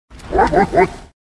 Category: Video Game Ringtones